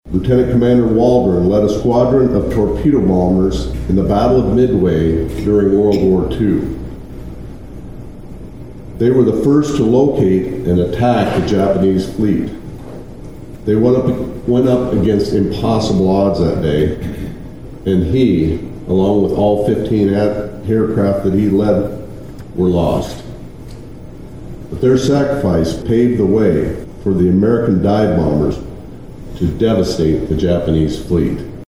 Rhoden also talked about the military service of the man who the bridge is named after.